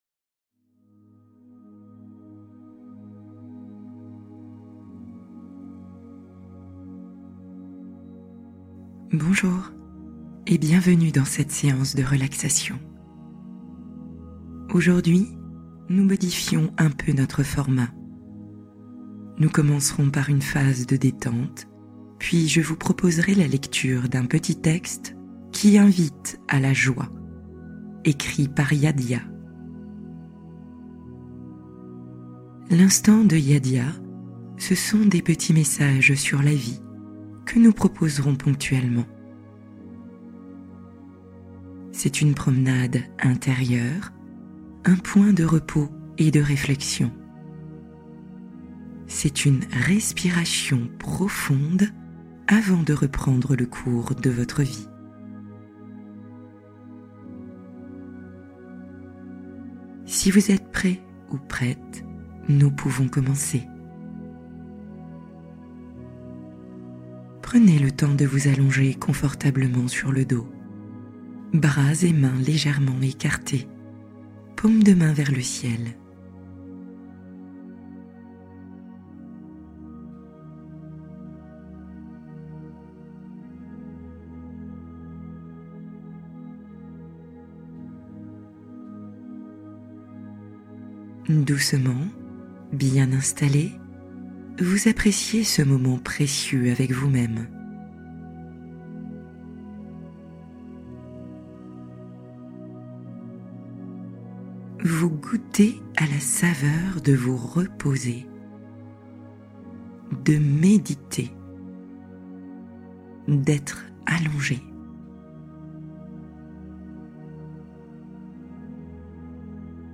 Les messages cachés de votre âme | Méditation pour réveiller une joie profonde